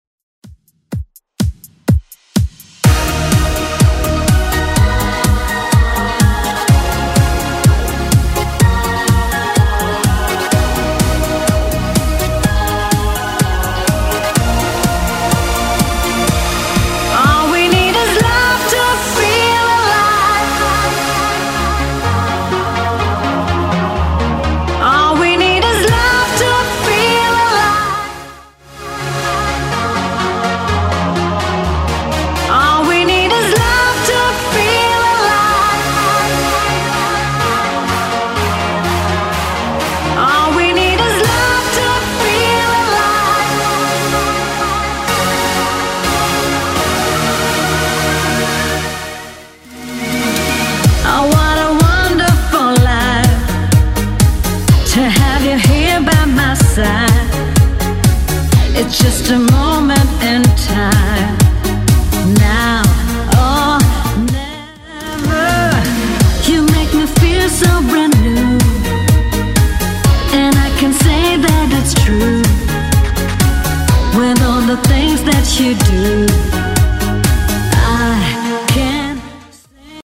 BPM: 125 Time